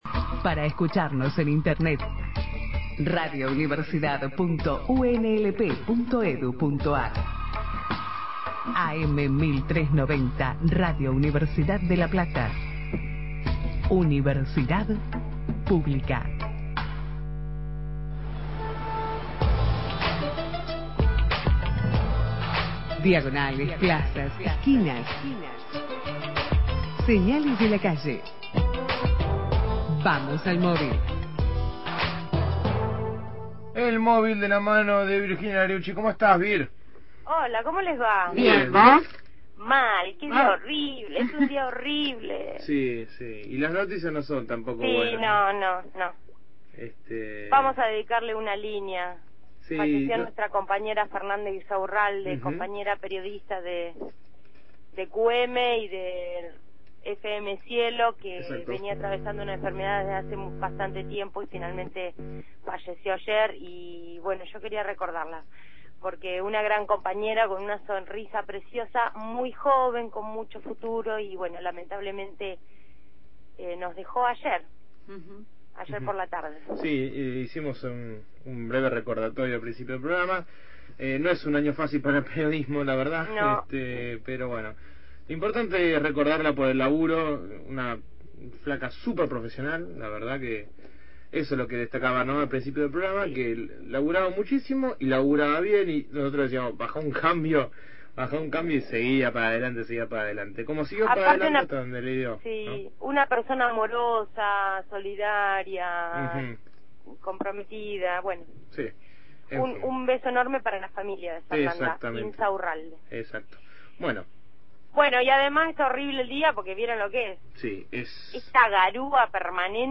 MÓVIL/ Estudiantes de la UNLP premiados mundialmente – Radio Universidad